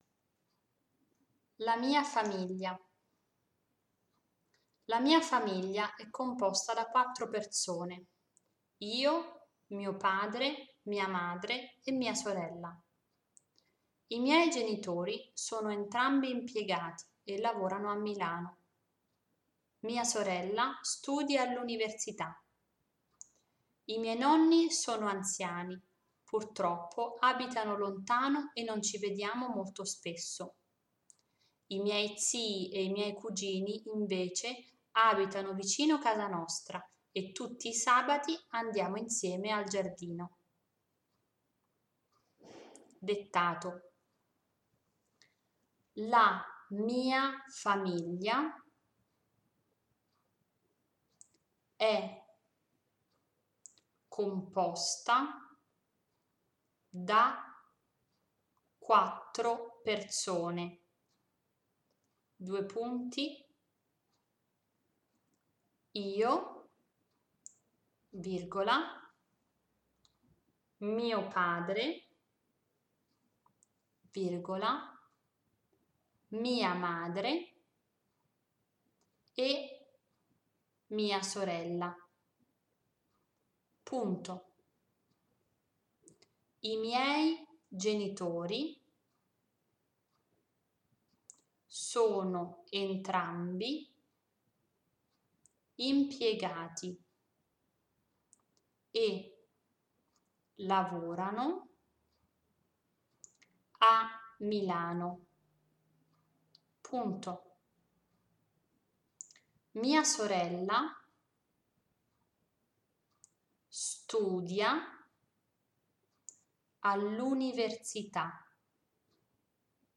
In this section you will find mp3 audio files that you can use to practise dictation in Italian.
The text will be read once (you don't have to write), then dictated (you have to start writing) and then read one last time to check the spelling.
Dettato-La-mia-famiglia.mp3